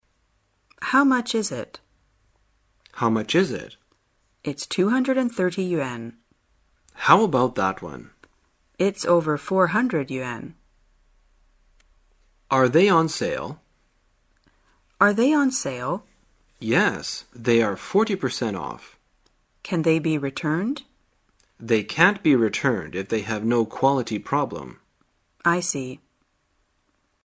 在线英语听力室生活口语天天说 第152期:怎样询问商品价格的听力文件下载,《生活口语天天说》栏目将日常生活中最常用到的口语句型进行收集和重点讲解。真人发音配字幕帮助英语爱好者们练习听力并进行口语跟读。